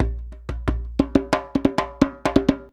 089DJEMB11.wav